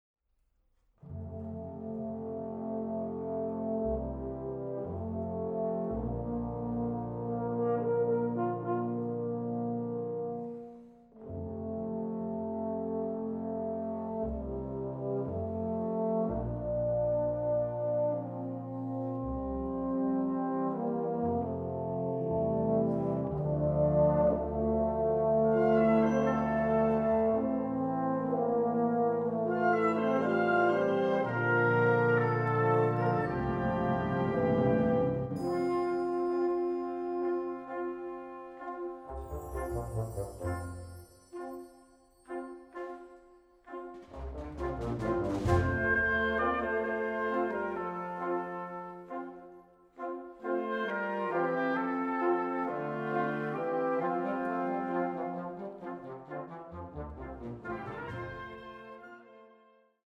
Musik für sinfonisches Blasorchester
Sinfonisches Blasorchester